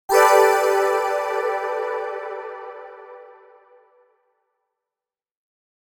Fast Short Shimmering Sound Effect
Description: Fast short shimmering sound effect. Quick and sparkling shimmering transition sound effect, ideal for cinematic intros, logo reveals, idents, videos, games, presentations, and highlights. Adds a bright, magical, and energetic touch in a short, impactful burst.
Genres: Sound Logo
Fast-short-shimmering-sound-effect.mp3